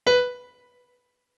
MIDI-Synthesizer/Project/Piano/51.ogg at 51c16a17ac42a0203ee77c8c68e83996ce3f6132